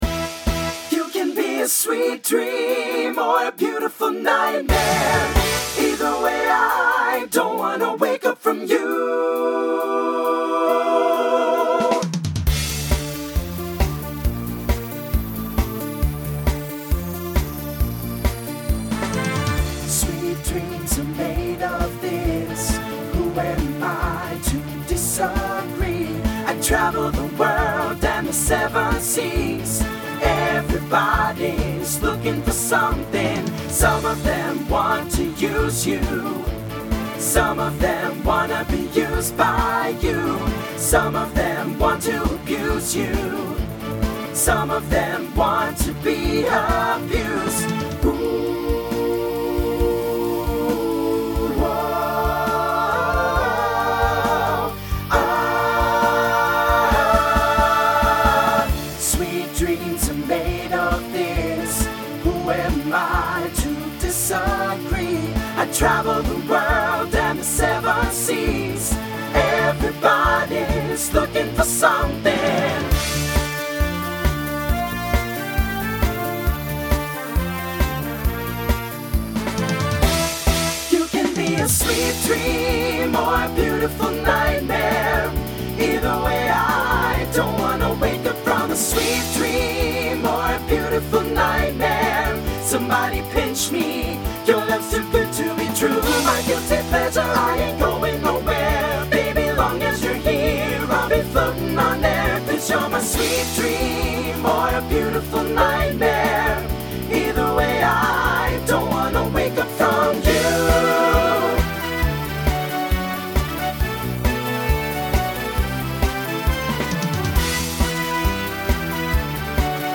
SATB SSA
SSA Instrumental combo Genre Rock Decade 1980s